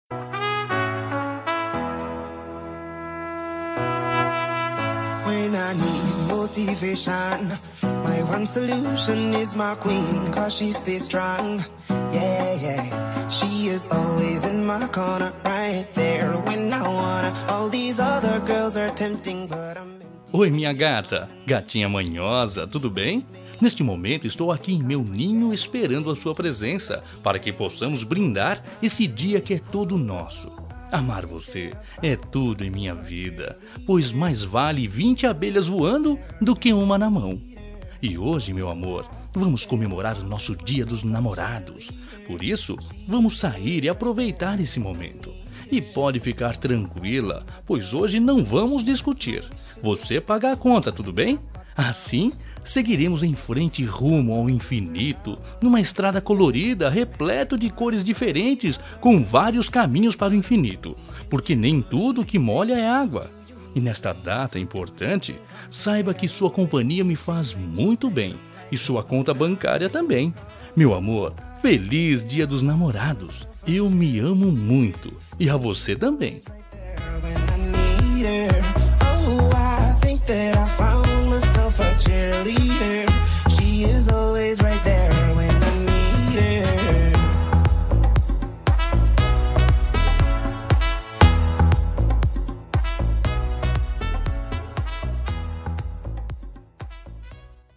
Gozação-Romântica
Voz Masculina